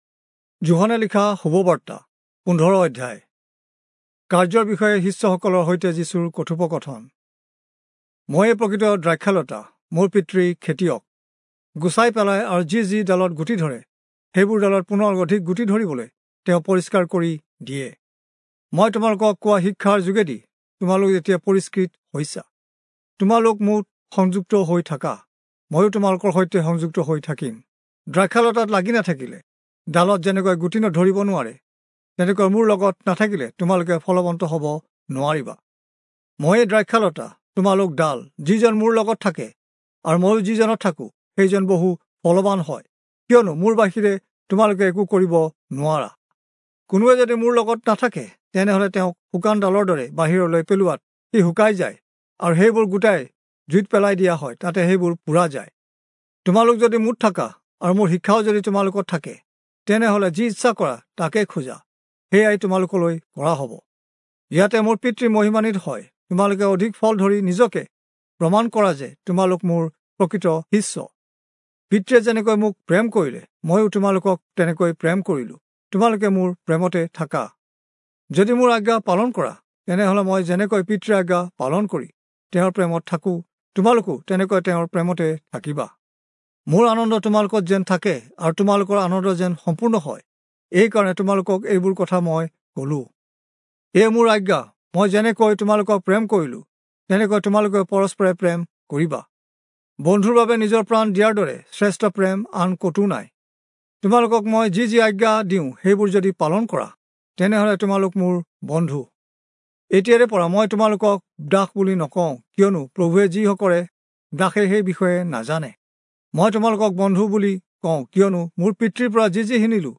Assamese Audio Bible - John 14 in Akjv bible version